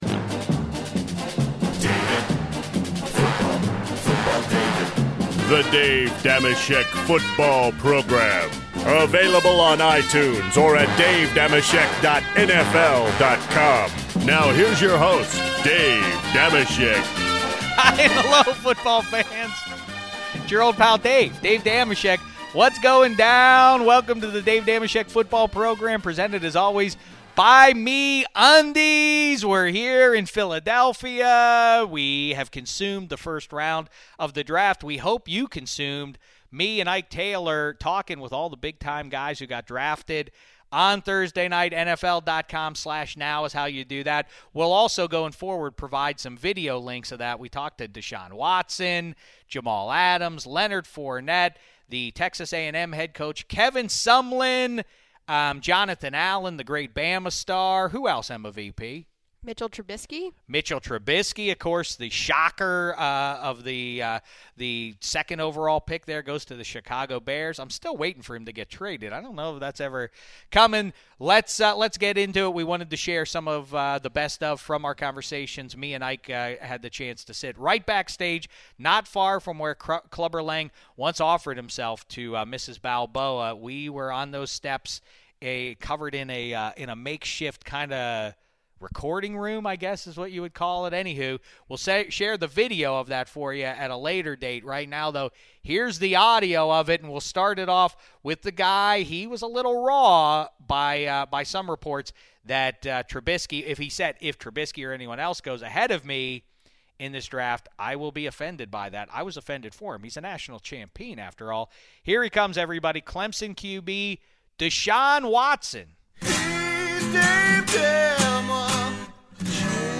Dave Dameshek is joined by Ike Taylor in Philadelphia, the site of the 2017 NFL Draft, to interview the top players selected in the first round, including Deshaun Watson, Leonard Fournette, Mitchell Trubisky, Jamal Adams and Jonathan Allen. The fellas ask the NFL's newest rookies about their emotions leading up to being drafted and how they plan to contribute to their teams in 2017. The guys also interview Texas A&M head coach Kevin Sumlin to discuss the impact his former player, Myles Garrett will have on the Cleveland Browns' defense.